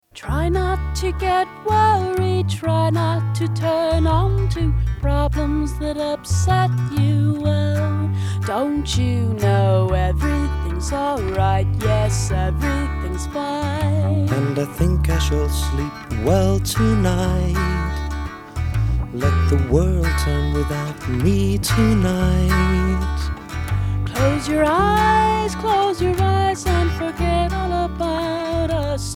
Genre : Musical Theatre